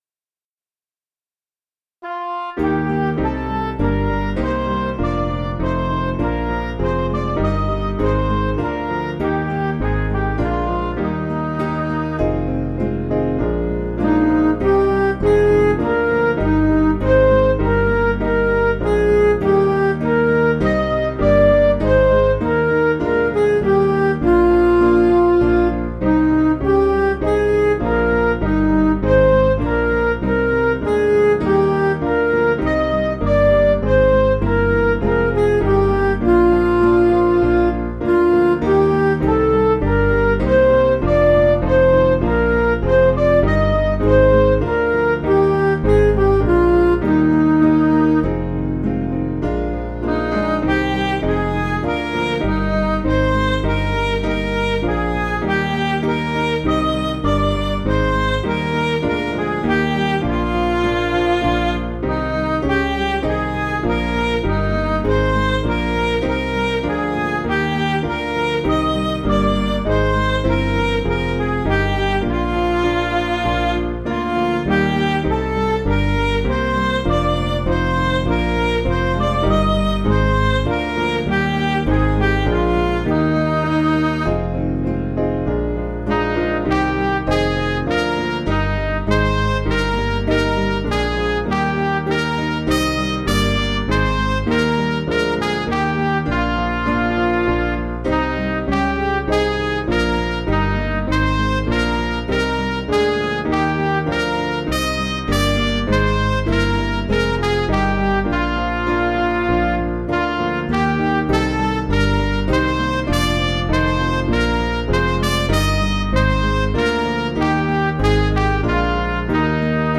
Small Band
(CM)   3/Eb 482.1kb